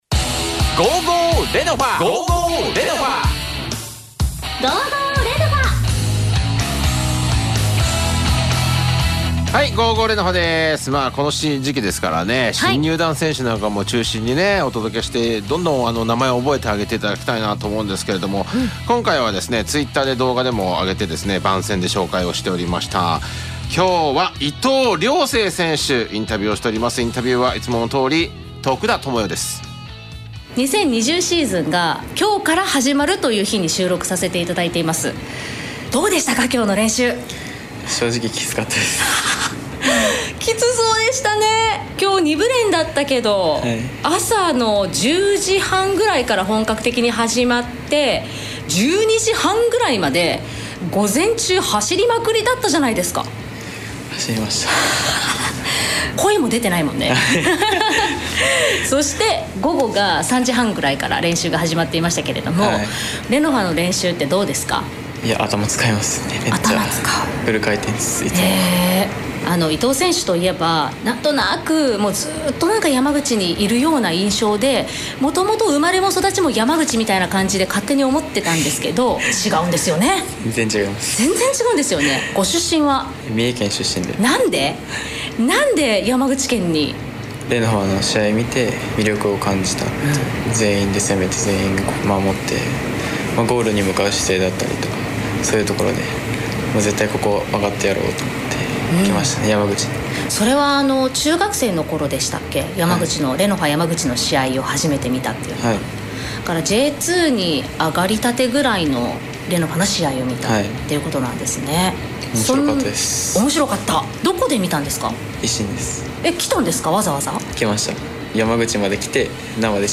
エフエム山口で毎週金曜日17:40～17:50放送中
レノファ山口の選手たちが、毎週出演！